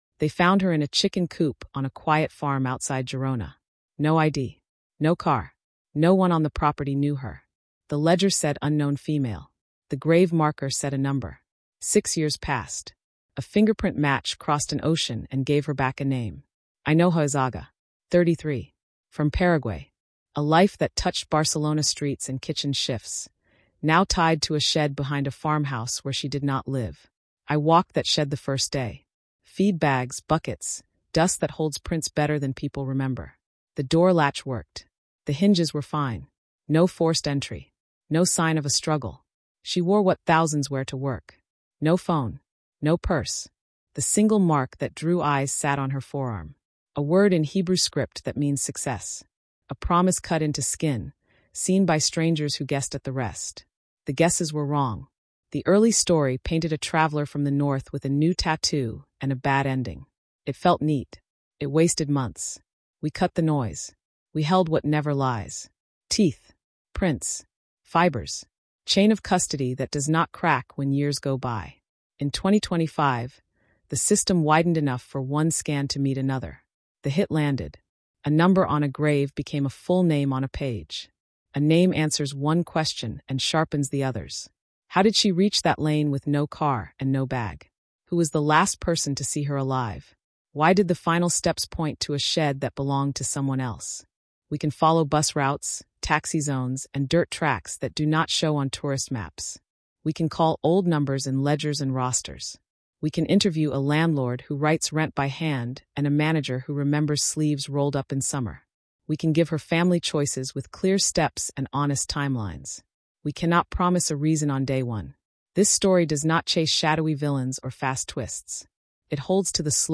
You hear the case through an expert narrator who keeps the focus on facts, dignity, and family.
This is a cold case told with clear steps, steady pace, and real investigation.